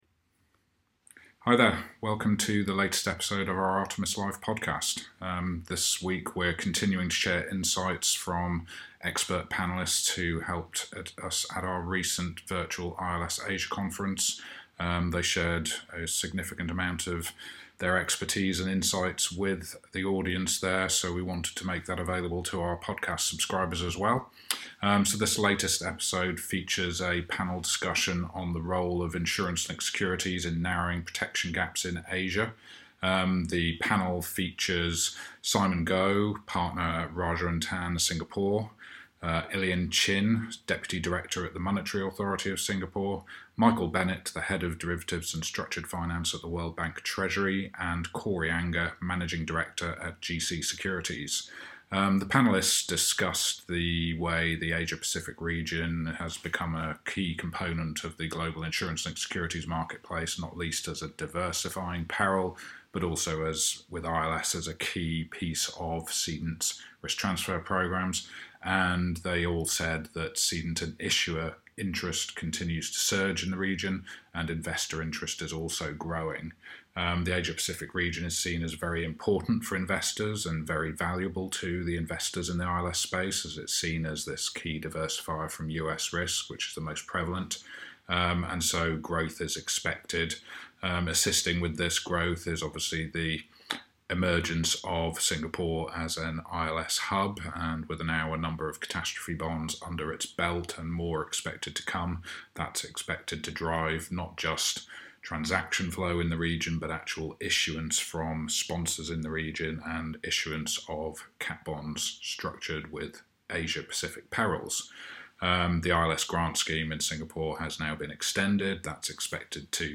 This podcast episode features panellists at our recent virtual ILS Asia conference discussing important role of insurance-linked securities (ILS), such as catastrophe bonds and other capital markets backed reinsurance tools, in narrowing and closing protection gaps in the Asia Pacific region.